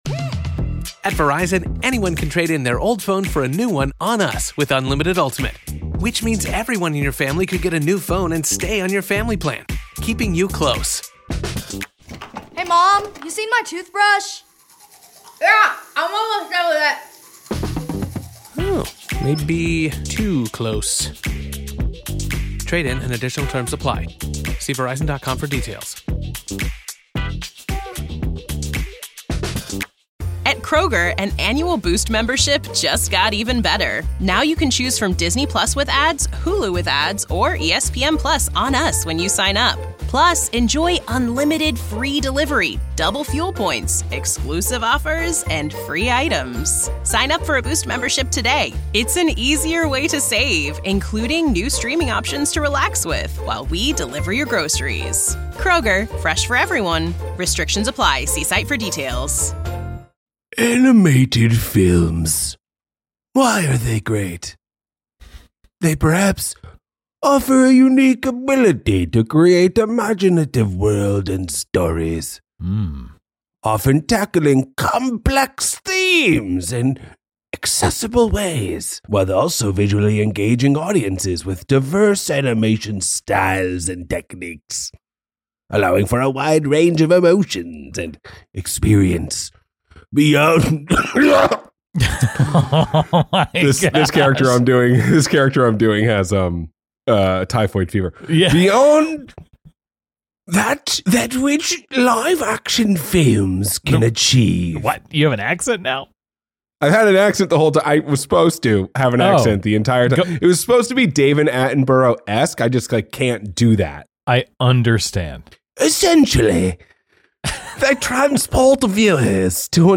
Longtime friends discuss the movies and television they love.